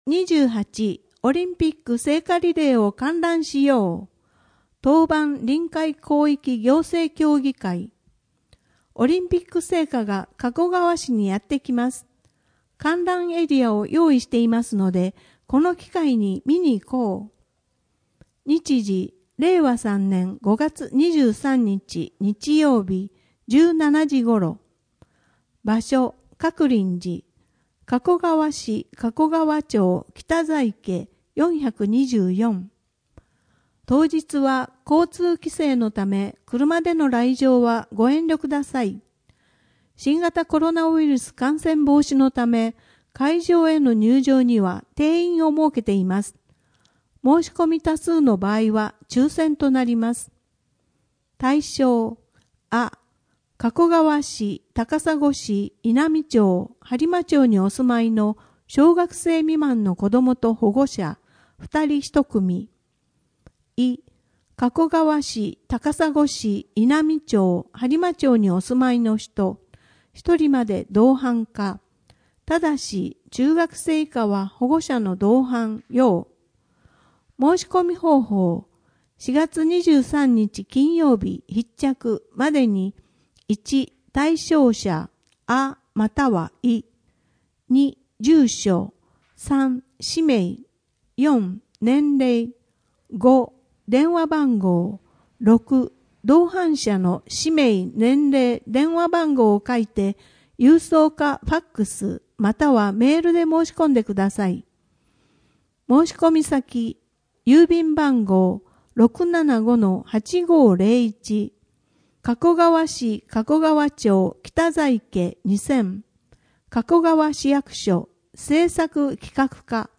声の「広報はりま」4月号
声の「広報はりま」はボランティアグループ「のぎく」のご協力により作成されています。